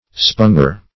Sponger \Spon"ger\ (-j[~e]r), n.